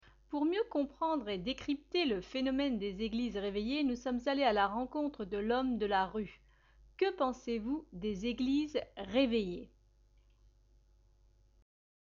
Eglises réveillées : Vox pop